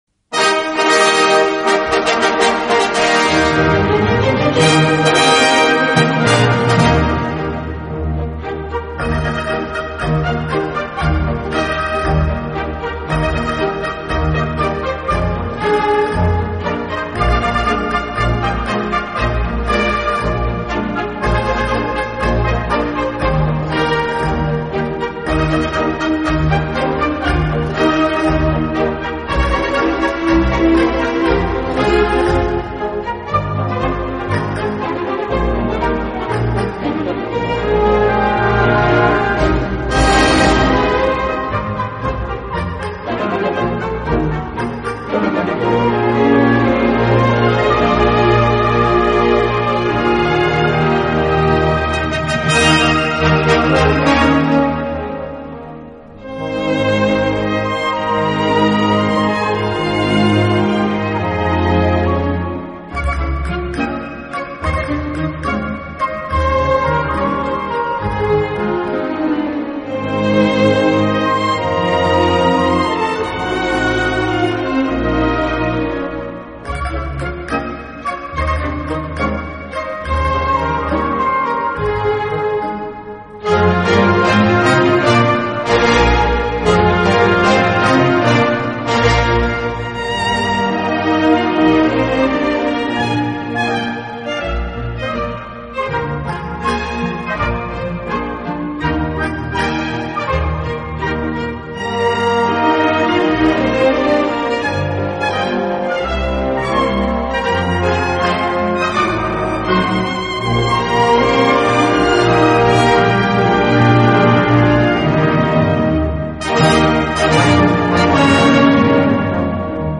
的演出方式，自己边拉小提琴边指挥乐队。